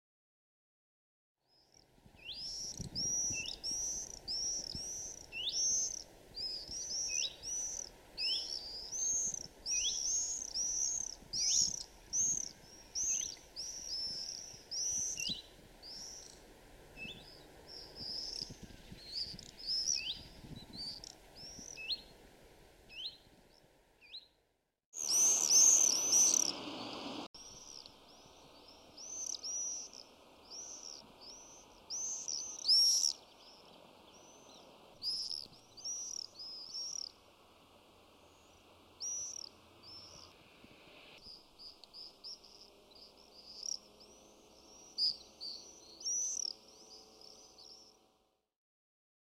Kuuntele: Tervapääsky